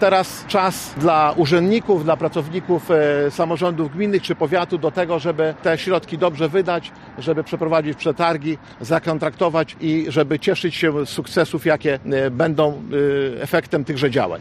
O wykorzystaniu środków mówił podczas konferencji prasowej Lech Marek Szabłowski, starosta powiatu łomżyńskiego: